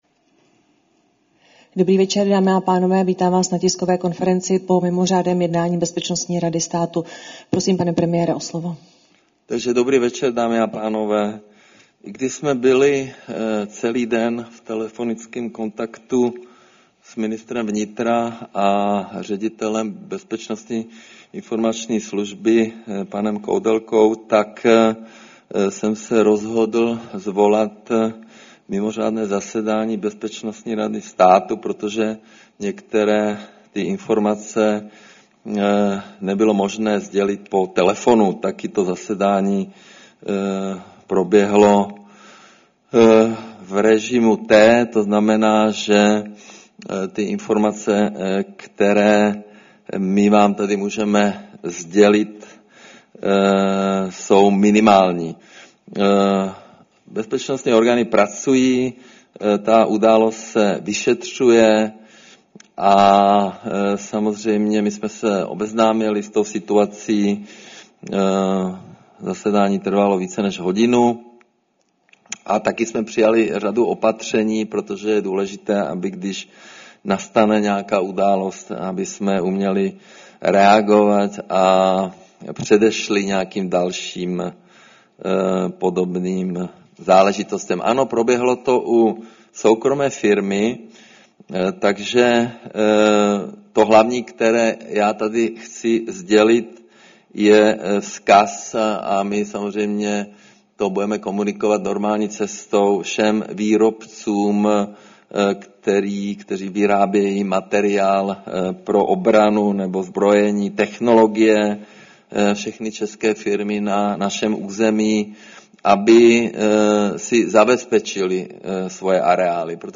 Tisková konference po mimořádném jednání Bezpečnostní rady státu, 20. března 2026